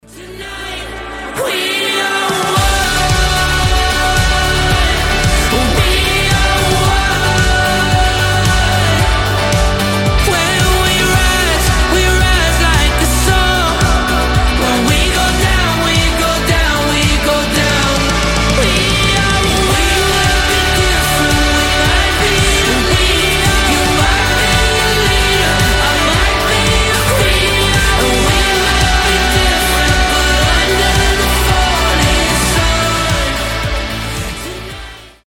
indie pop
воодушевляющие